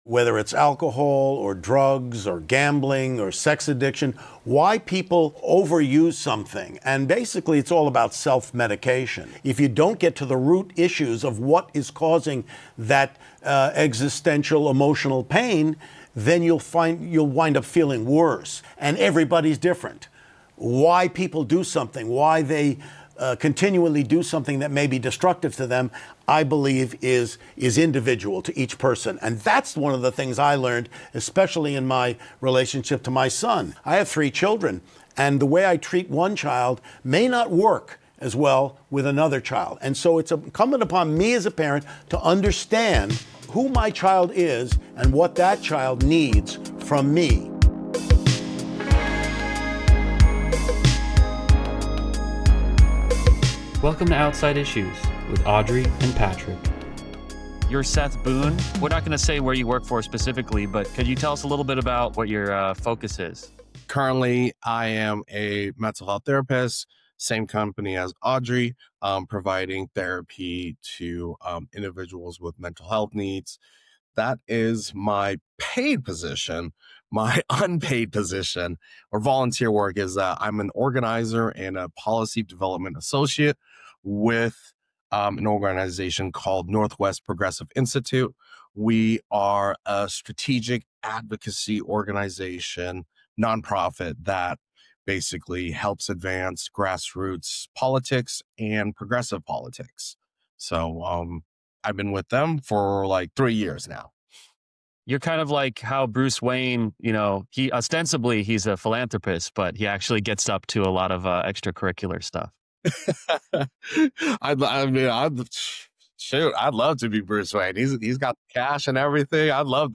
Opening quote by Rob Reiner.